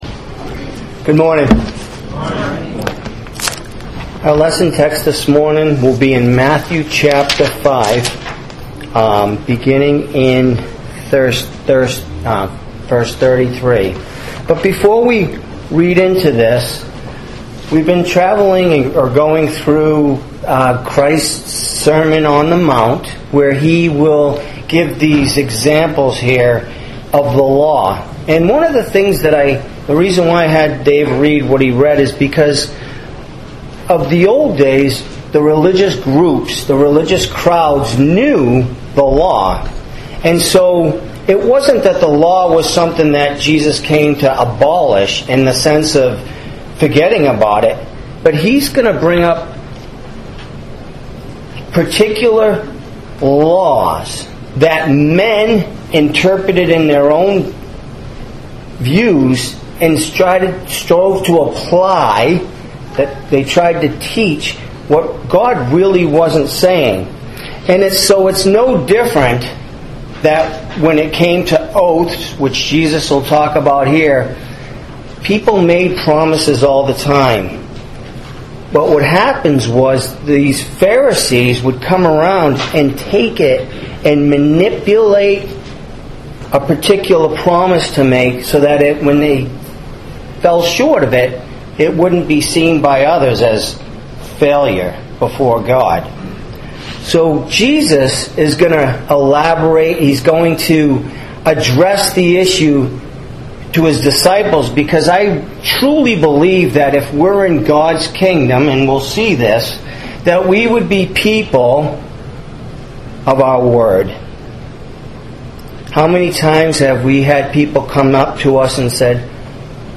Sermon on the Mount Truthfulness